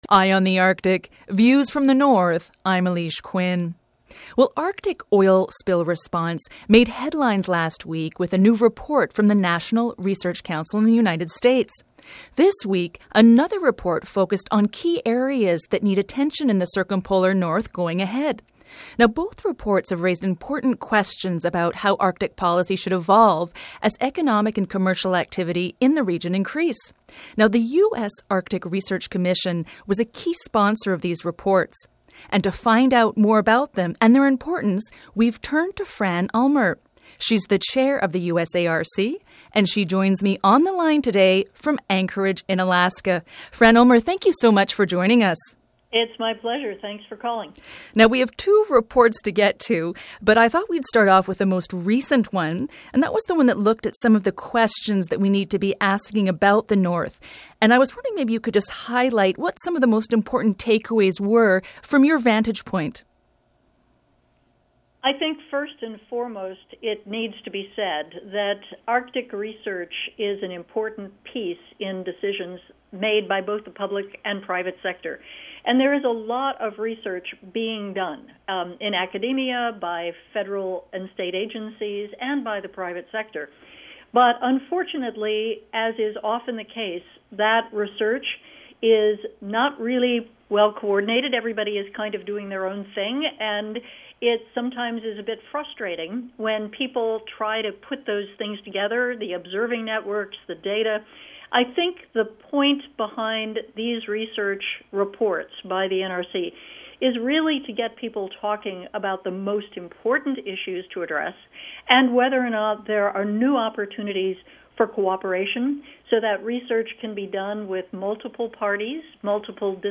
Feature Interview: What questions should we be asking about the Arctic’s future?